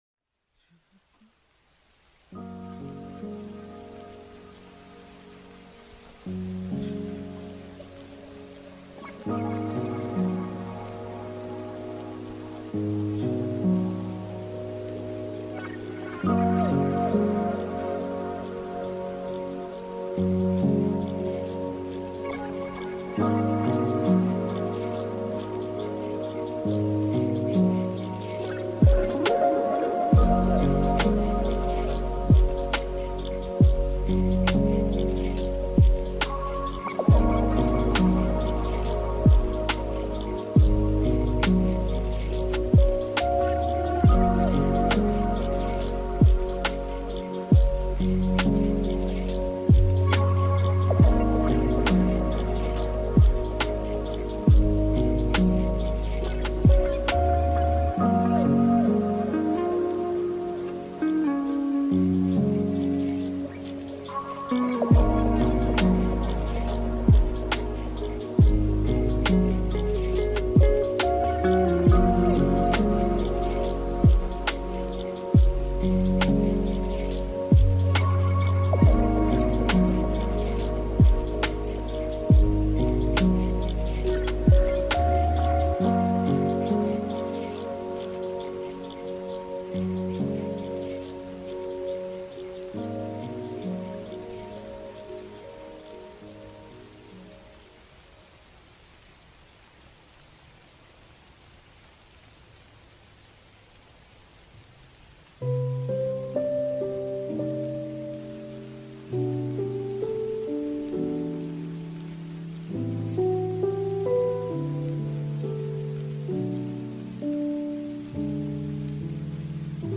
your ultimate destination for calming vibes, chill beats
lo-fi music